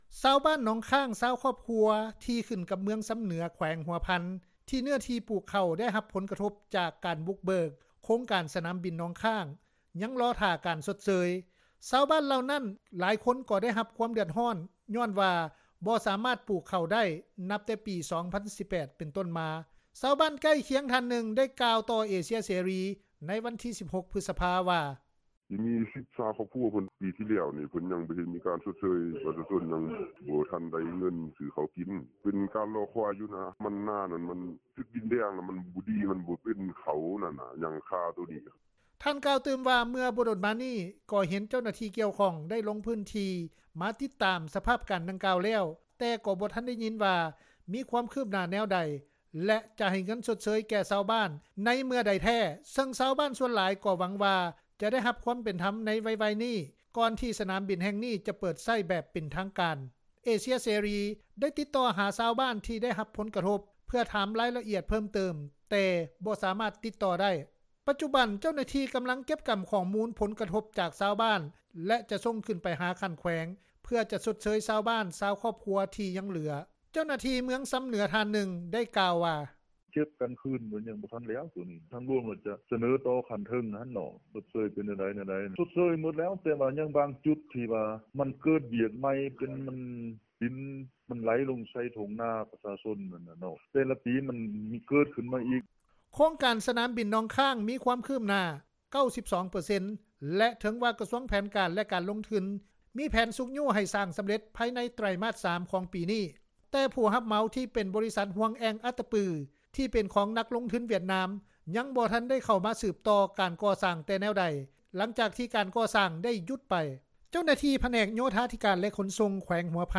ເຈົ້າໜ້າທີ່ເມືອງຊຳເໜືອ ທ່ານນຶ່ງ ໄດ້ກ່າວວ່າ:
ຊາວບ້ານເມືອງຊຳເໜືອ ທ່ານ ນຶ່ງ ກ່າວວ່າ: